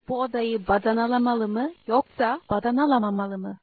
turkish tongue twisters - Learn Turkish
(Boo oh-dah-yih bah-dah-nah-lah-mah-lih mih, yoke-sah bah-dah-nah-lah-mah-mah-lih mih?)